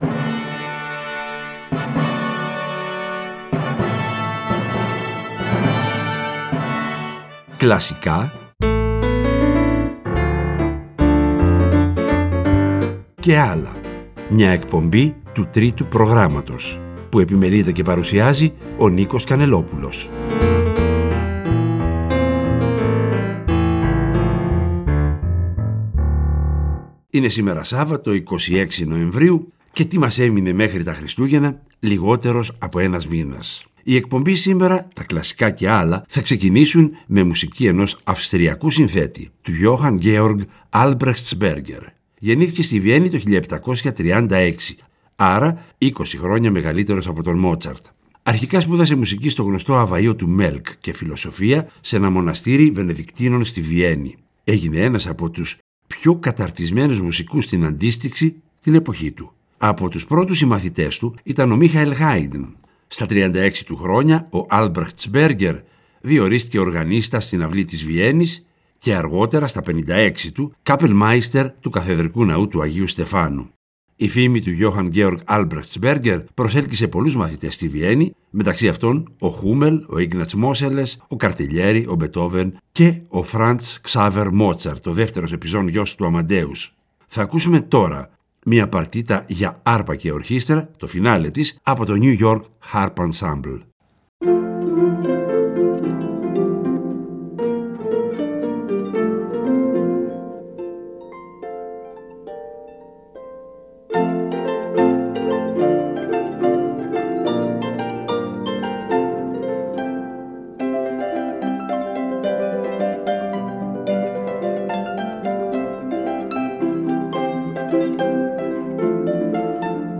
Μέσα από τις εκπομπές αυτές θα αποκαλύπτονται τα μυστικά της μουσικής, οι μικρές και μεγάλες ιστορίες, γνωστών και άγνωστων έργων, γνωστών και άγνωστων συνθετών. Και, προς το τέλος κάθε εκπομπής, θα ακούγονται τα… «άλλα» μουσικά είδη, όπως μιούζικαλ, μουσική του κινηματογράφου -κατά προτίμηση σε συμφωνική μορφή- διασκευές και συγκριτικά ακούσματα.